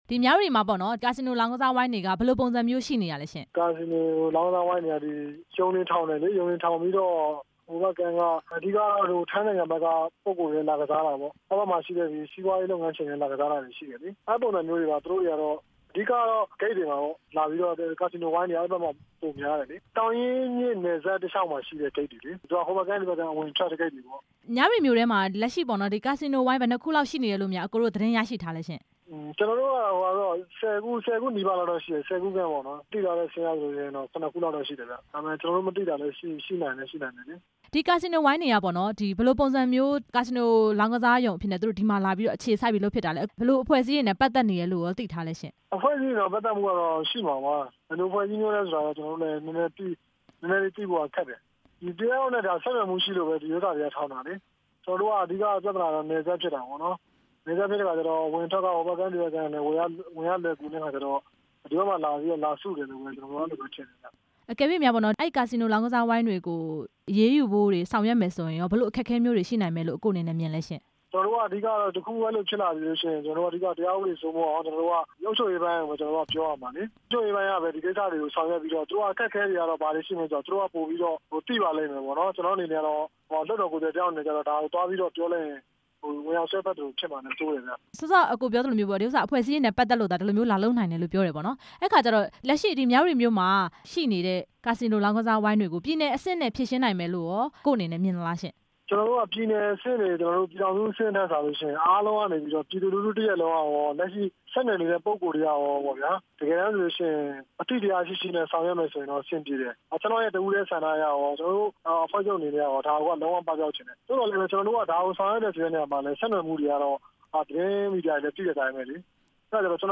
မြဝတီမြို့က လောင်းကစားရုံတွေကို အရေးယူမယ့်အကြောင်း မေးမြန်းချက်